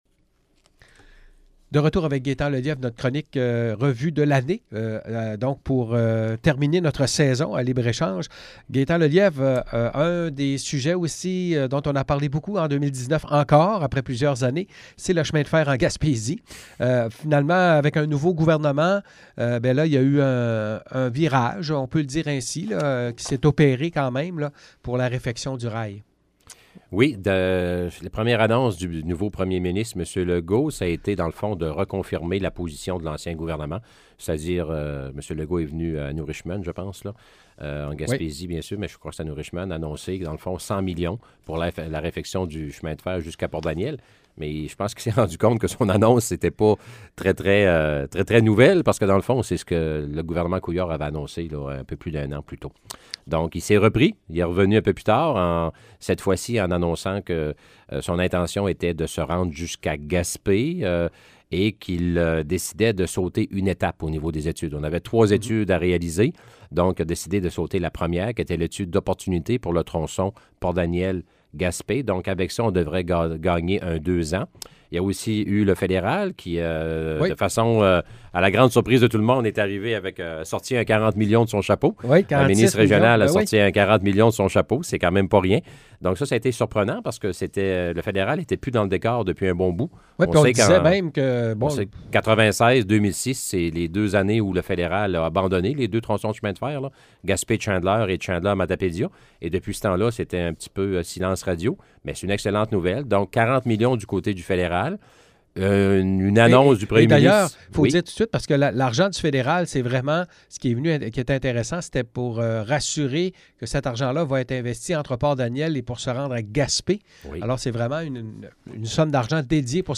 Jeudi, nous avons reçu notre chroniqueur politique Gaétan Lelièvre, pour une revue de l’année 2019.